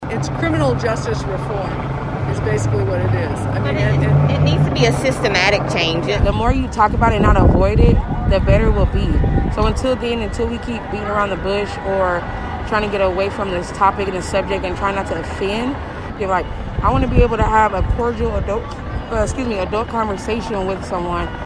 protester-montage.mp3